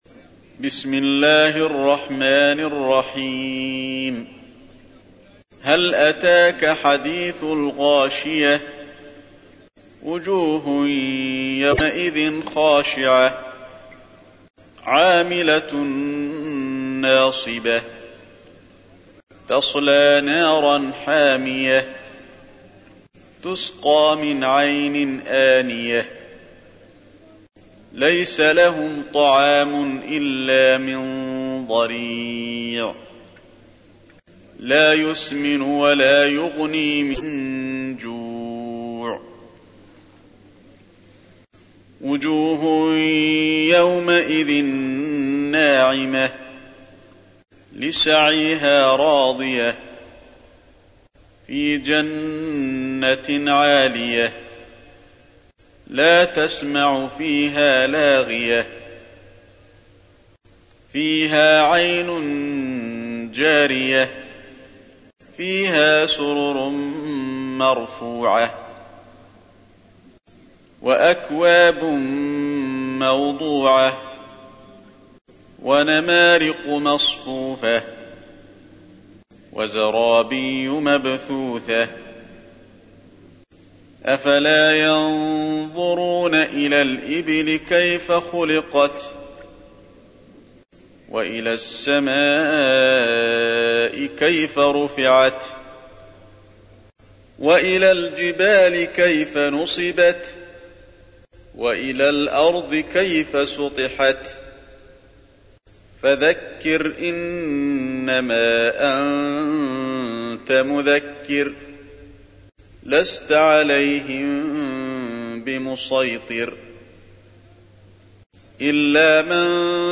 تحميل : 88. سورة الغاشية / القارئ علي الحذيفي / القرآن الكريم / موقع يا حسين